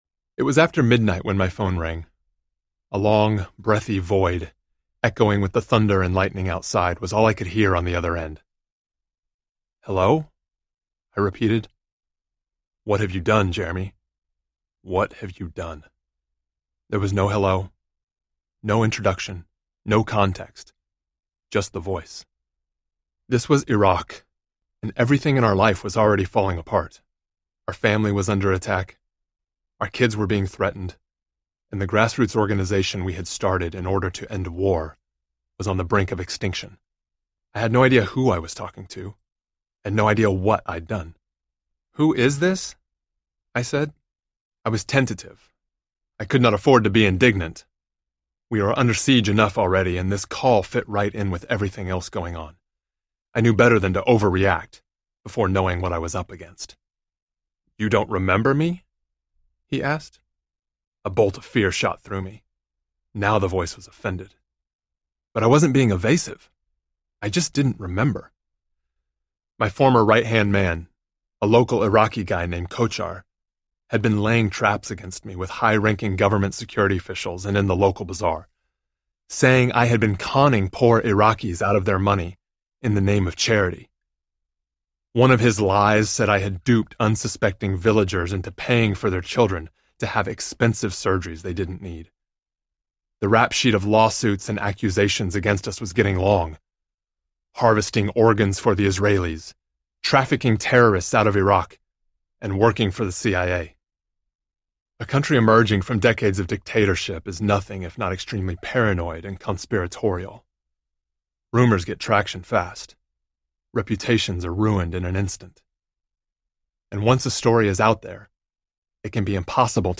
Love Anyway Audiobook